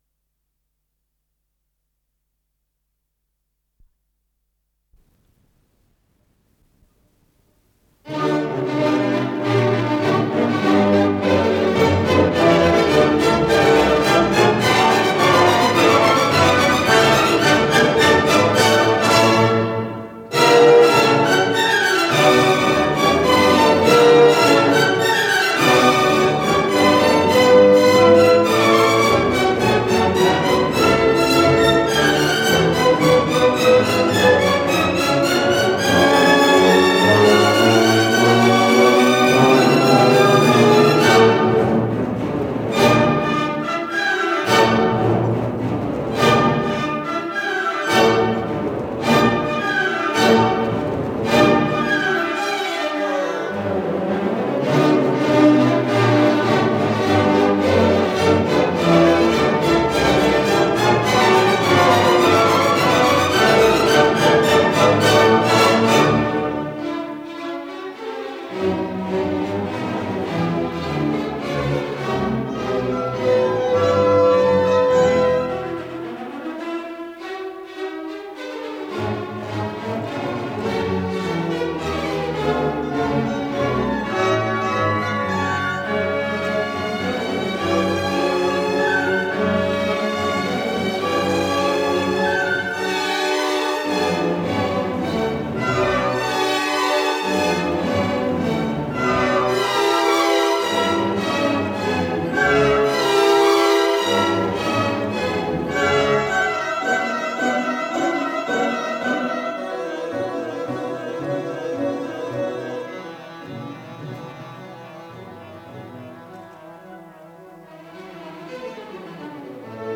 с профессиональной магнитной ленты
ПодзаголовокРе мажор
Содержание5. Финал, Аллегро кон фуоко (темпо ди полака)
ИсполнителиГосударственный симфонический оркестр СССР
Художественный руководитель и дирижёр - Евгений Светланов
ВариантДубль моно